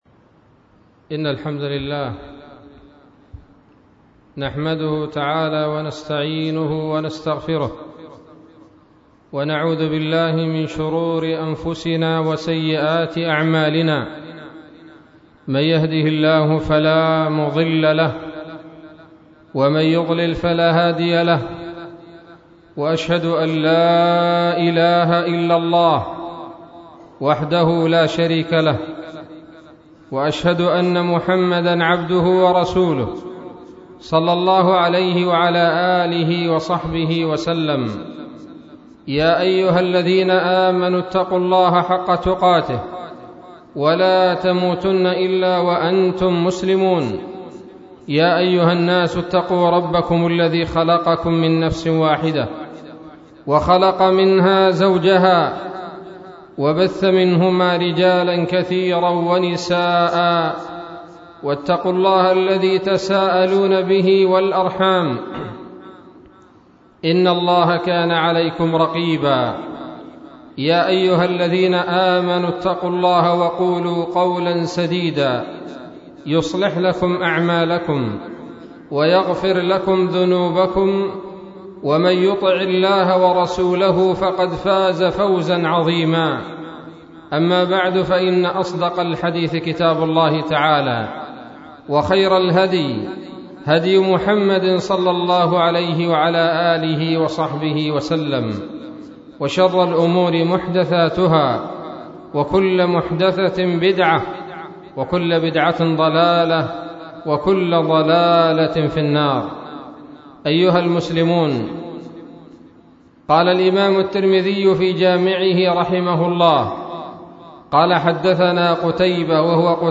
خطبة بعنوان : ((خيار الناس وشرارهم